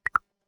complete.wav